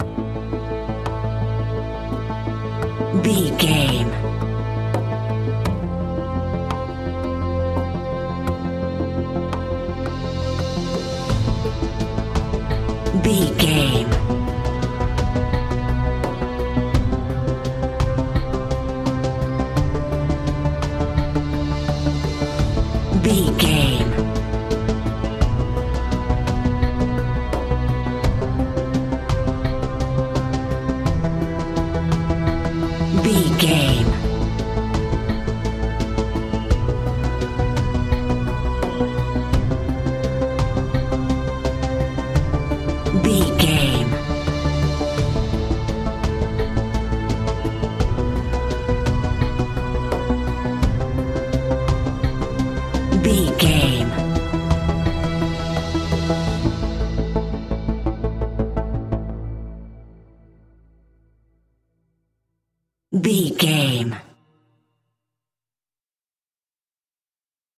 In-crescendo
Thriller
Aeolian/Minor
ominous
dark
haunting
eerie
strings
synthesiser
drums
horror music